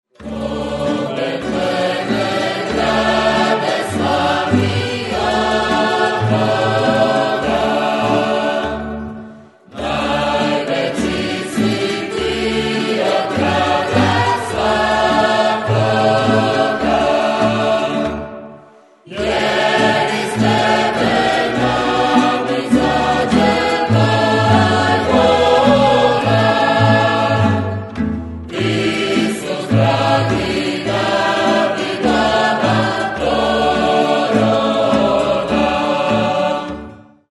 Drugi nosač zvuka KUD-a “Marof” sadrži trinaest popularnih božićnih skladbi.
O Betleme (narodna)